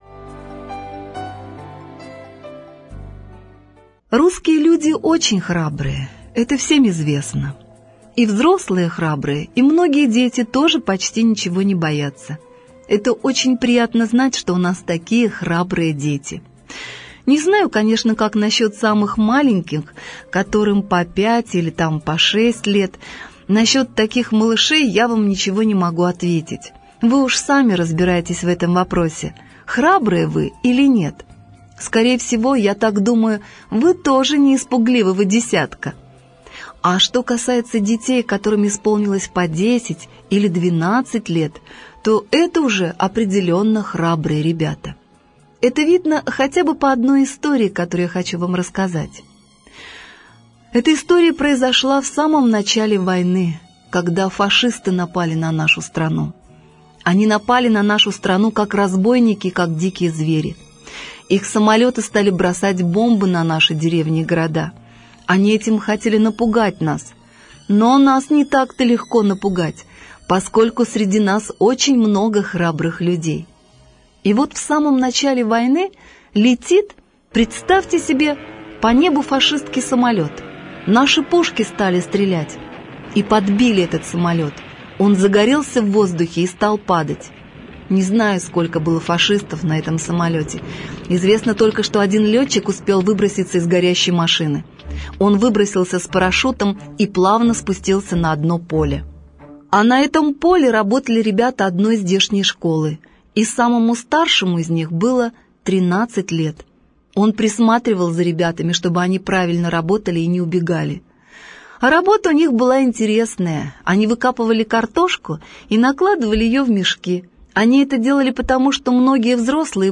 Аудиорассказ «Храбрые дети»